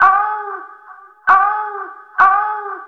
Index of /90_sSampleCDs/Zero-G - Total Drum Bass/Instruments - 3/track64 (Vox EFX)
06-Oh Oh Oh....wav